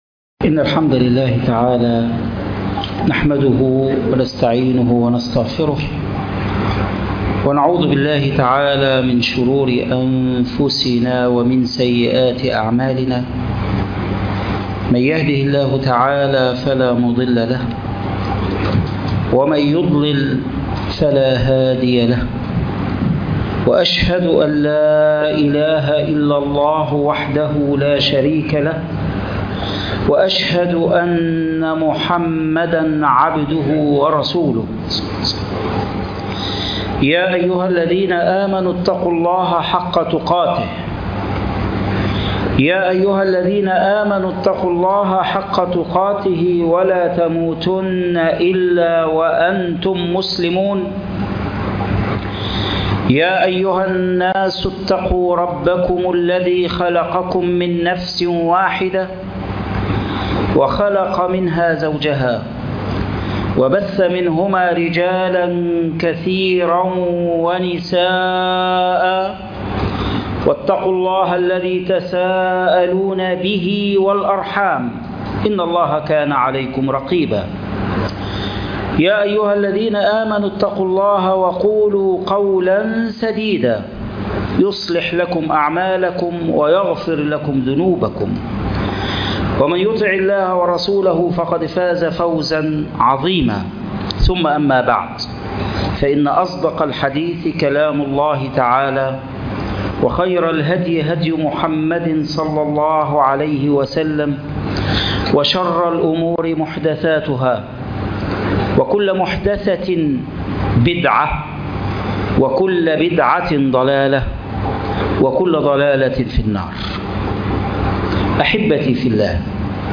تأملات في خطبة الوداع - خطبة الجمعة